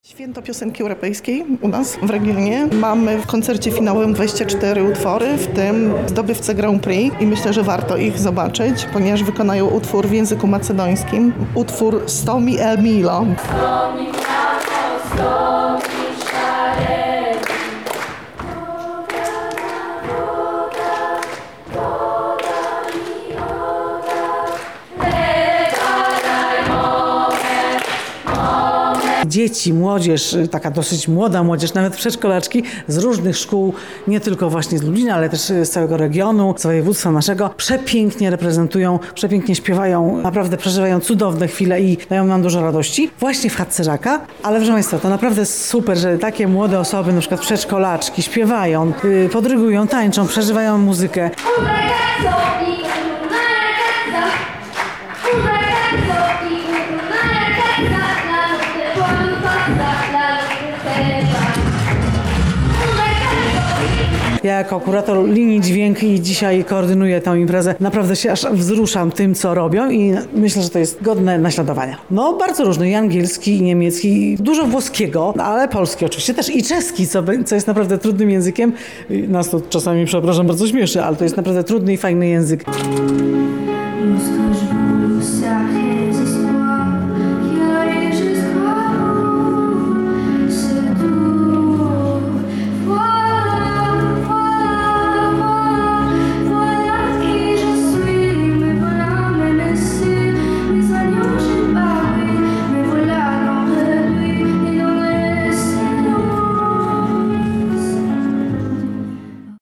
Usłyszeliśmy barwne głosy na XXII Wojewódzkim Festiwalu Piosenki Europejskiej.
6 maja w Akademickim Centrum Kultury i Mediów UMCS w Lublinie odbył się finał konkursu piosenki europejskiej. W konkursie wzięły udział dzieci w wieku 3-15 lat.
Relacja z konkursu piosenki
relacjazkonkursupiosenki.mp3